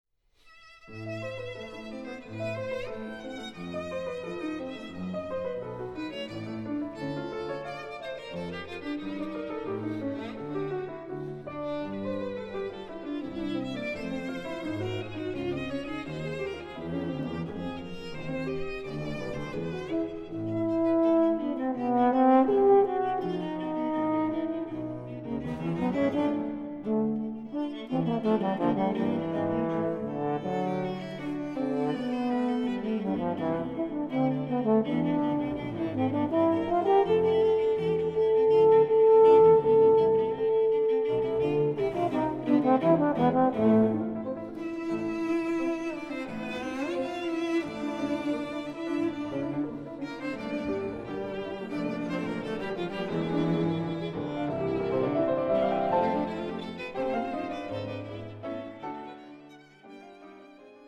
Quintet for French Horn, Violin, Viola, Cello and Piano